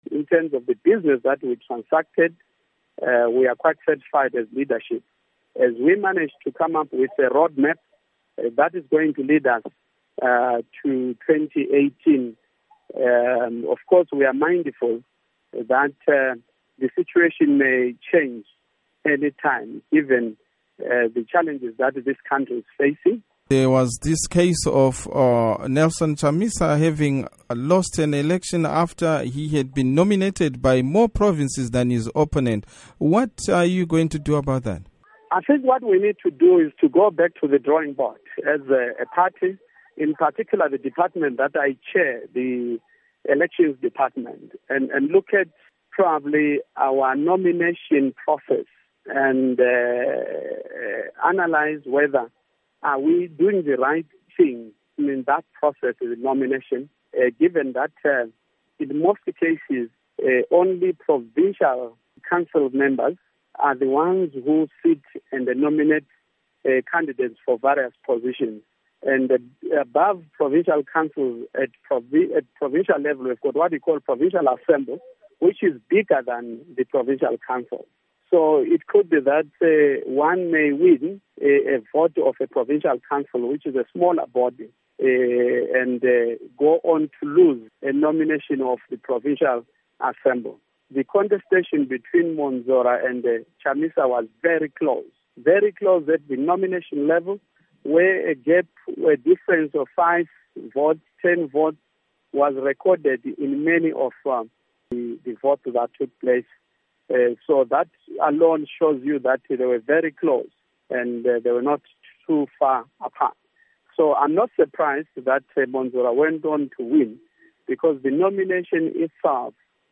Interview With Chairman Lovemore Moyo of MDC-T